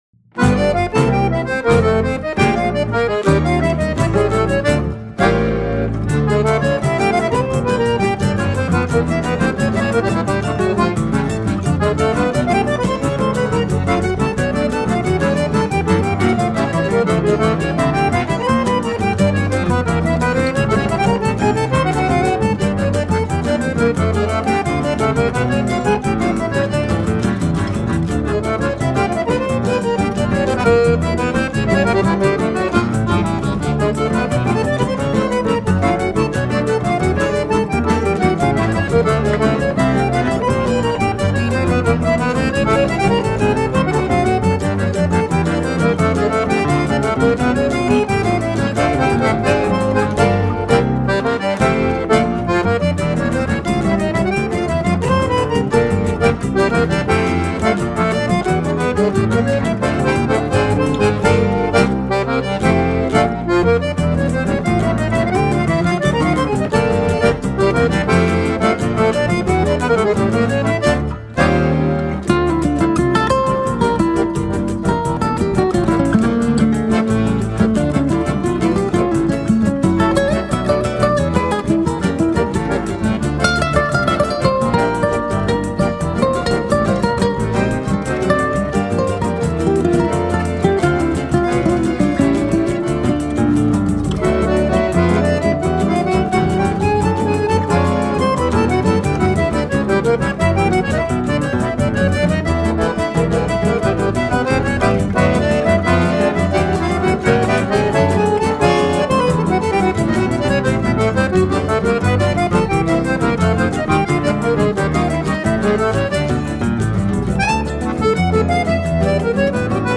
Acoordeon, Teclados
Violao Acústico 6
Violao 7
Cavaquinho
Baixo Elétrico 6
Pandeiro